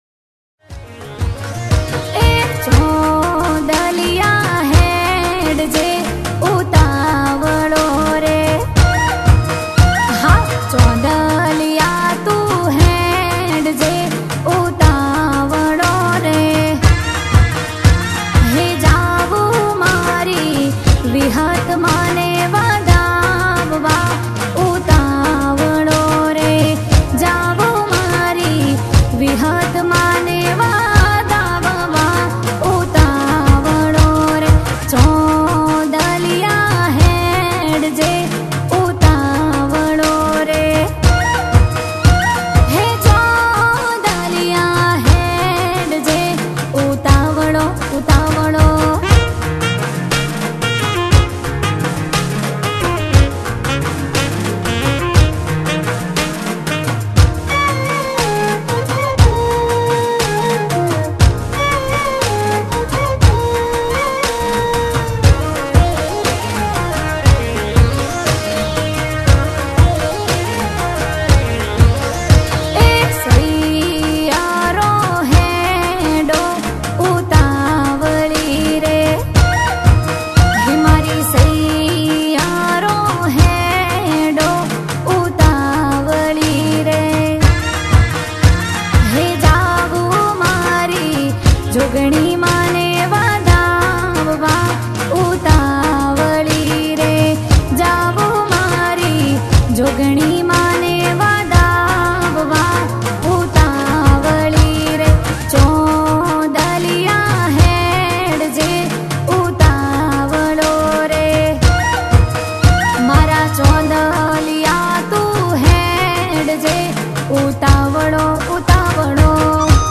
Navratri Garba Albums